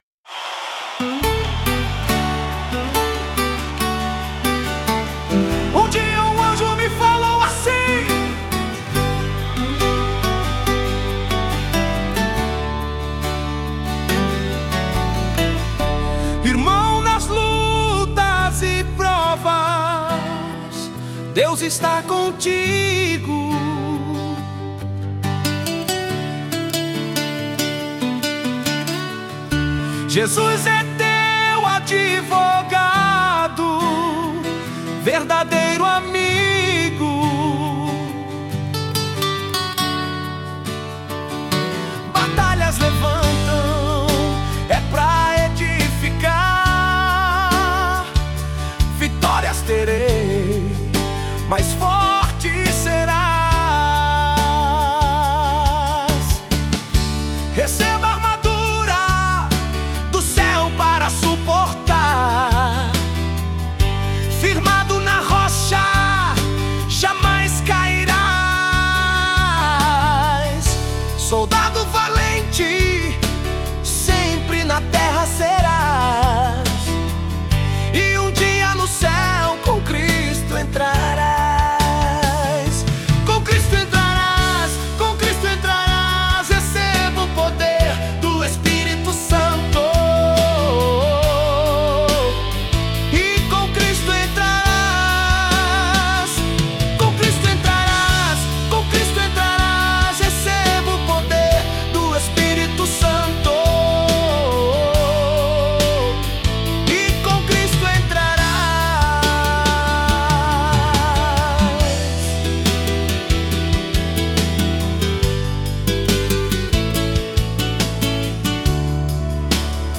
[Vocal Masculino]